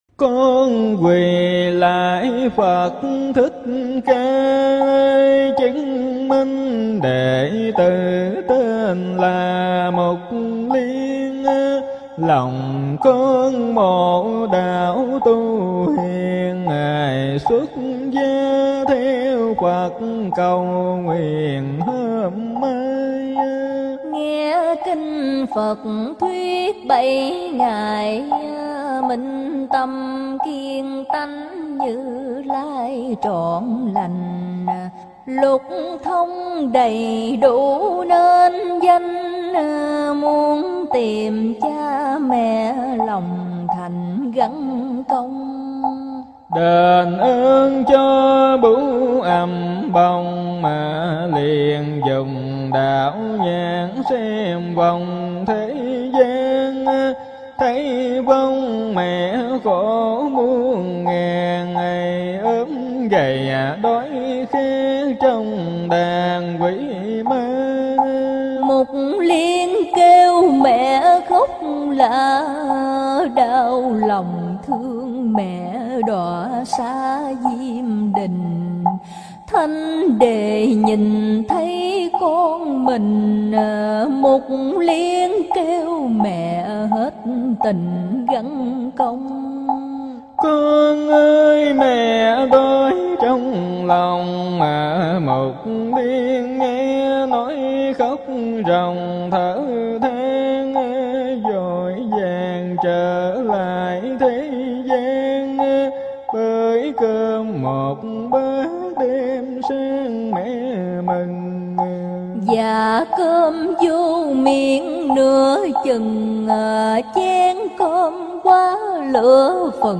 Đồng Tụng